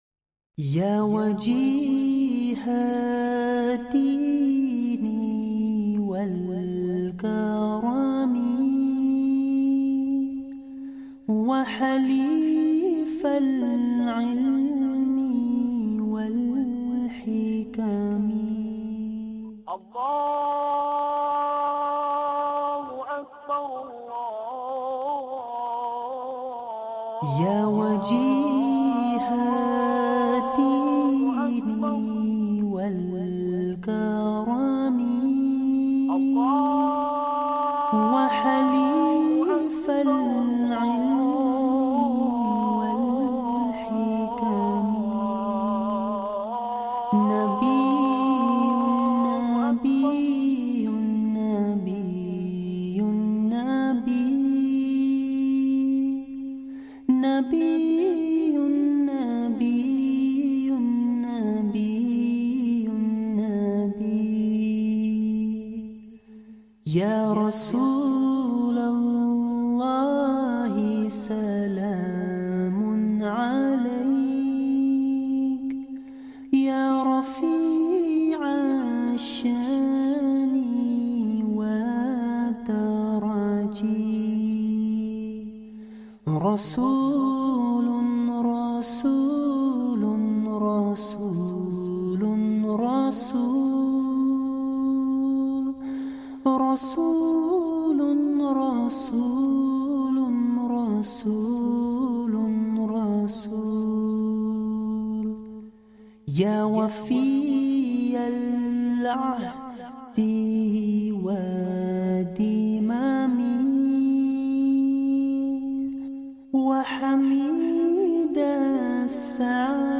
Islamic Youth Awakening – Nasheed